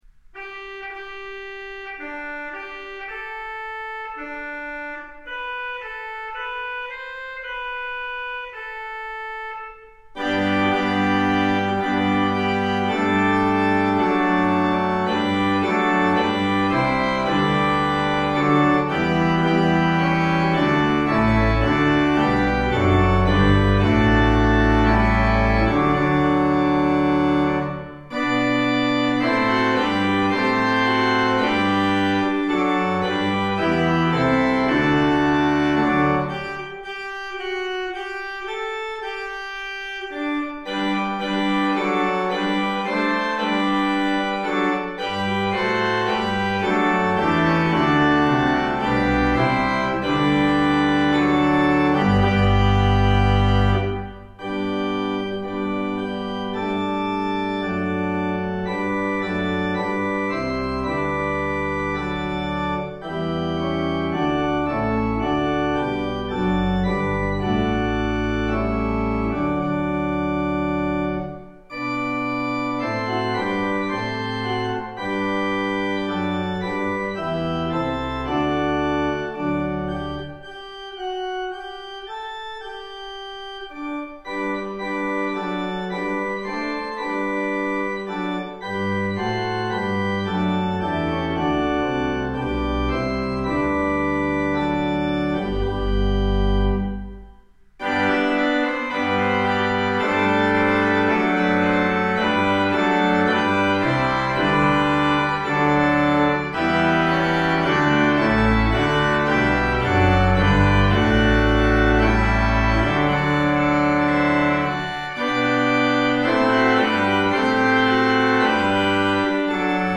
O come, all ye faithful – Organist on demand
Organ: Moseley